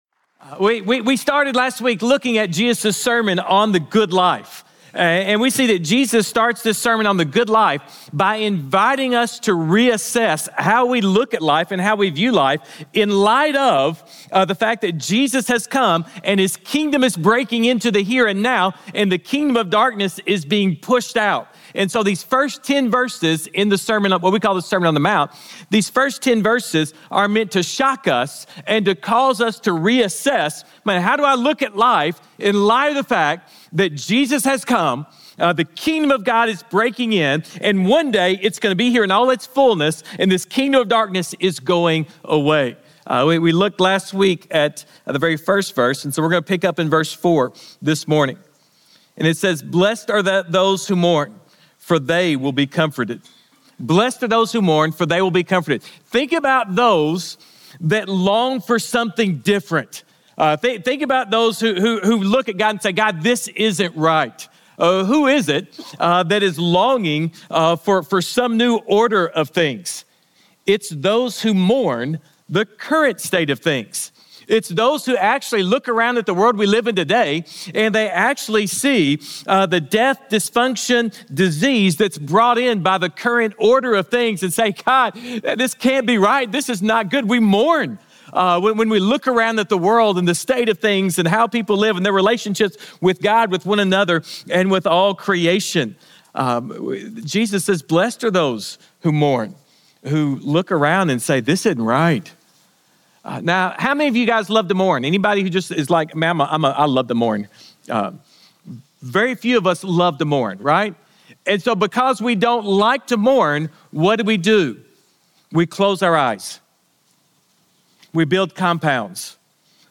Sermons | Grace Community Church